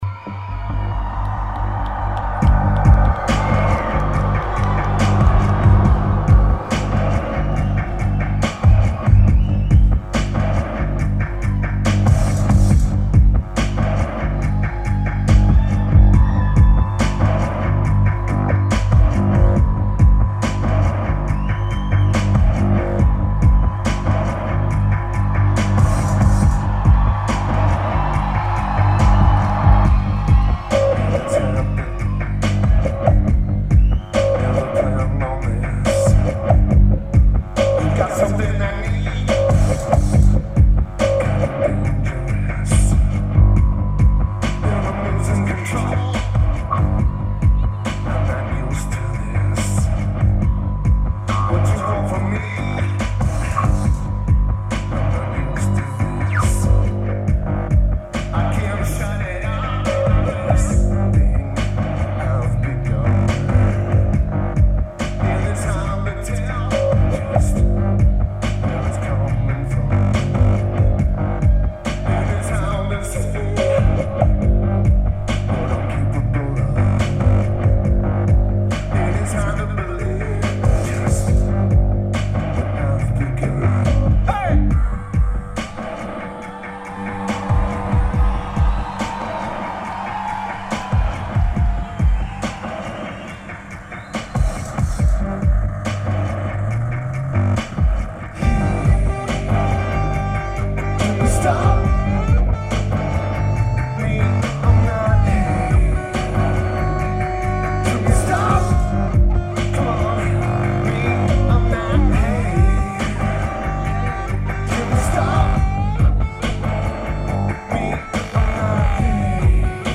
Berlin Germany
Keyboards/Bass/Backing Vocals
Drums
Guitar
Vocals/Guitar/Keyboards